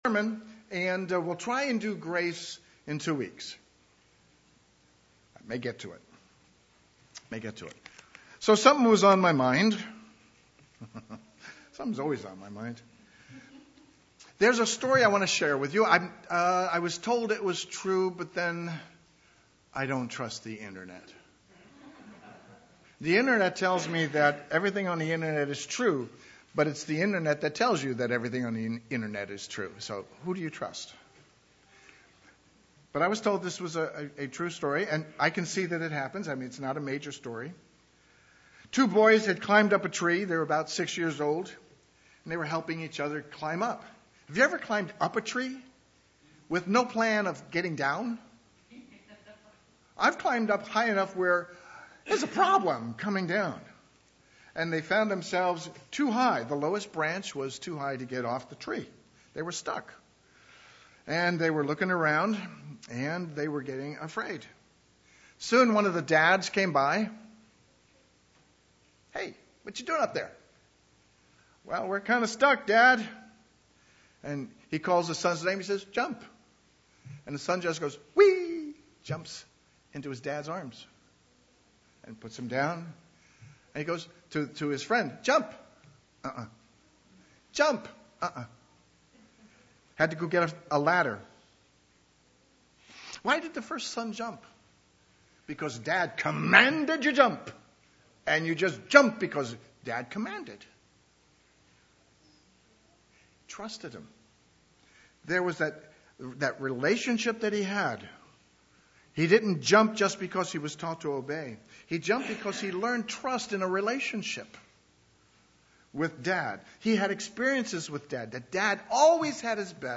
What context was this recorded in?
Given in Eureka, CA